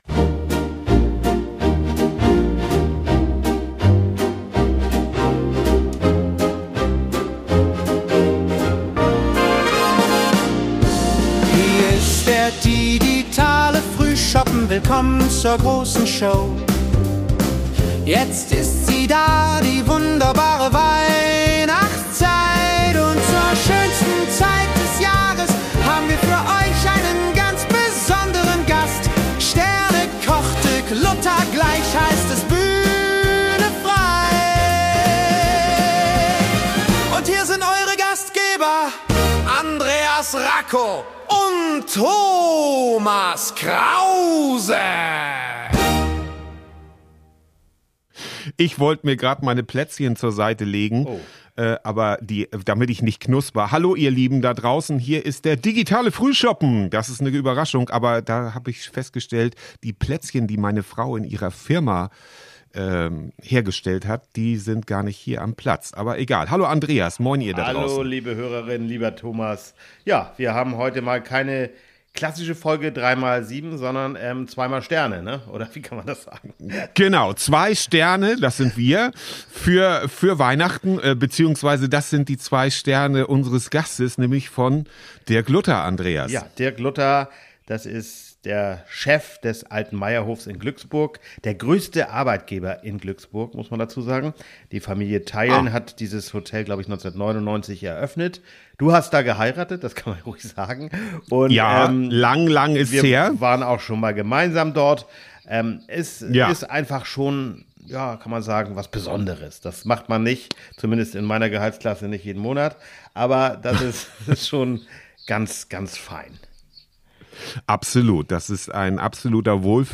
Heute führt uns der Digitale Frühschoppen an einen der schönsten Orte Norddeutschlands: nach Glücksburg, in das vielfach ausgezeichnete Hotel „Alter Meierhof“ direkt an der Flensburger Förde.